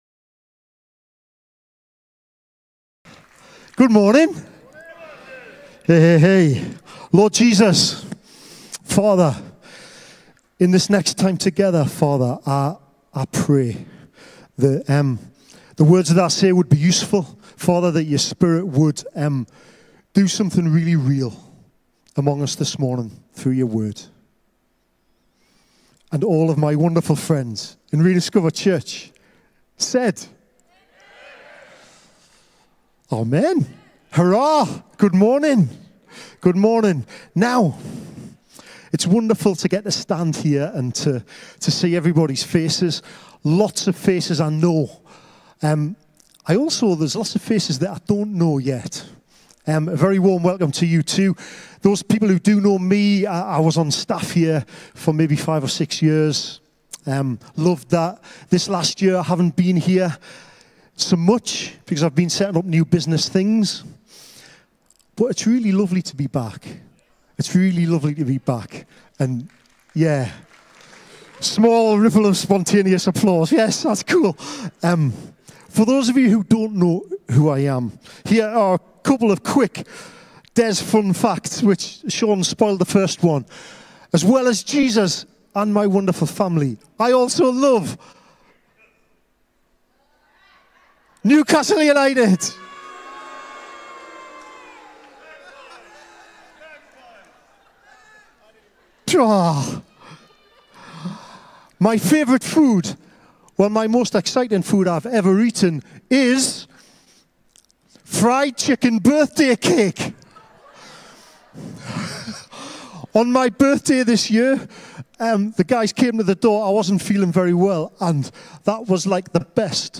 Rediscover Church Exeter | Sunday Messages What's Your Story?